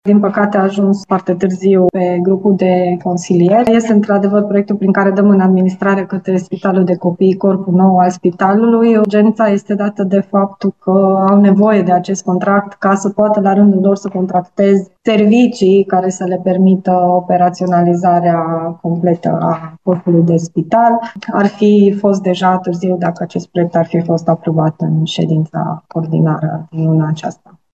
Noul corp de clădire va fi dat în administrare unității medicale pentru următorii 10 ani. Consiliul Local Municipal a aprobat proiectul în regim de urgență, explică viceprimarul Paula Romocean.